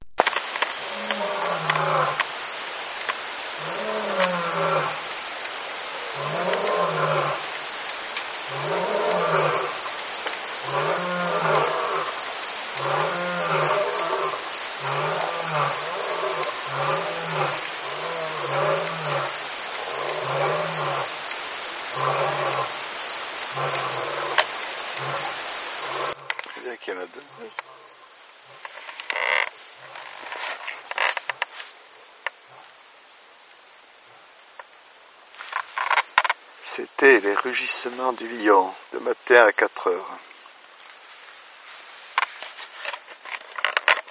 , filmée depuis la tente (mettre à fond le son de l'ordi.)
Va réécouter le lion, il est plus audible
lionson.mp3